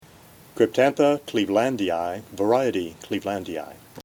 Pronunciation/Pronunciación:
Cryp-tán-tha  cleve-lánd-i-i